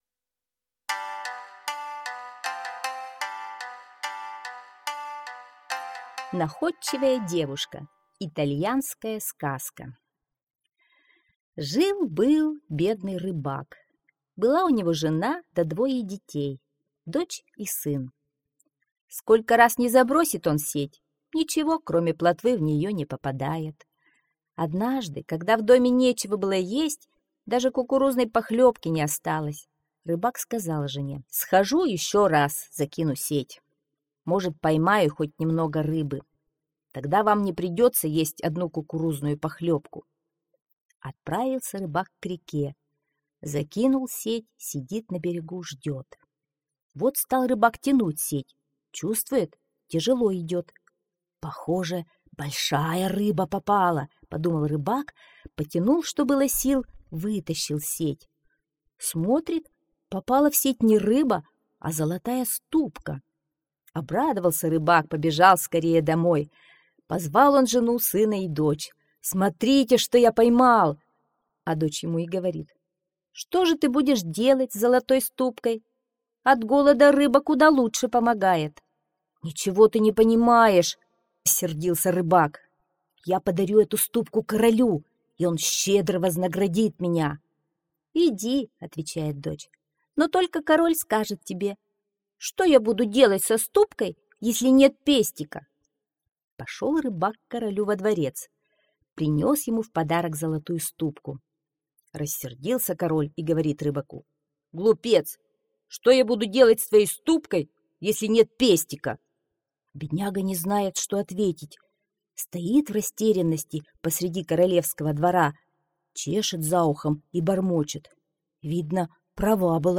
Находчивая девушка - итальянская аудиосказка. Жил-был бедный рыбак.